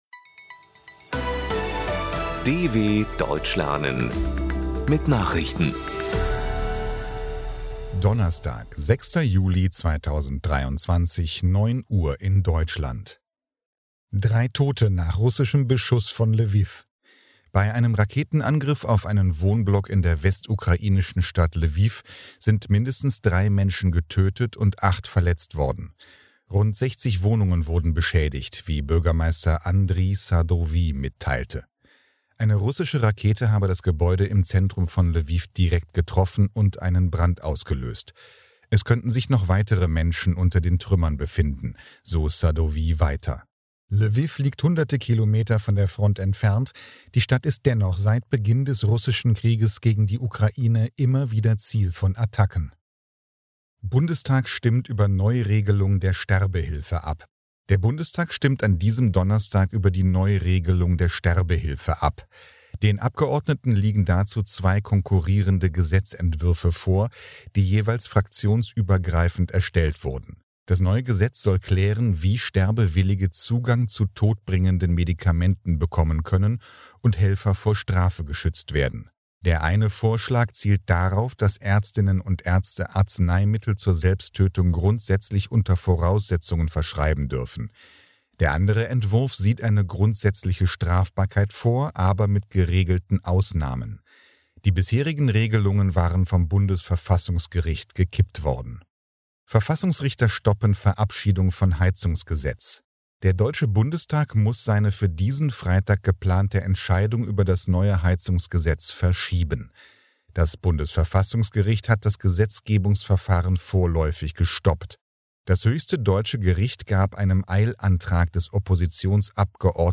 06.07.2023 – Langsam Gesprochene Nachrichten
Trainiere dein Hörverstehen mit den Nachrichten der Deutschen Welle von Donnerstag – als Text und als verständlich gesprochene Audio-Datei.